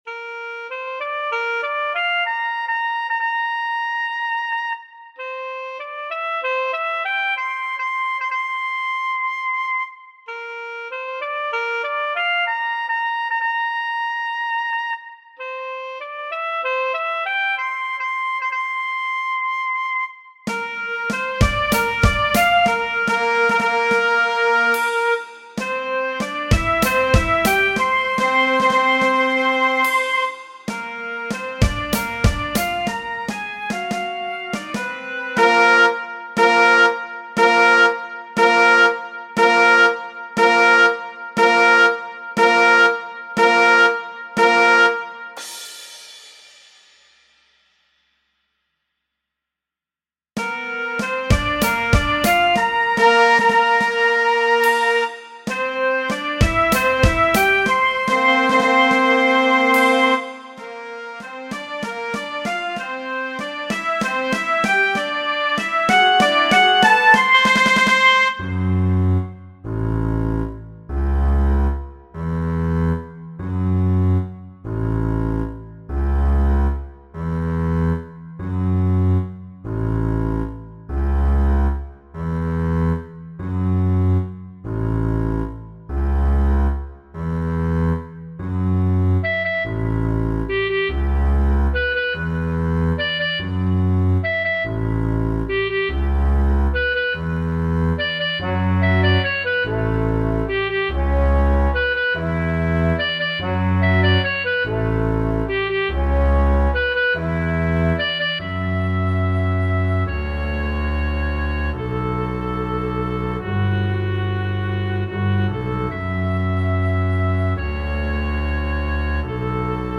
Je gaat met de astronauten die naar Mars gaan mee in dit muziekstuk: in deel 1 voel je de trots van de astronauten als ze aankomen bij de raket en gelanceerd worden. In deel 2 voel je de verveling van de eeuwige ruimte.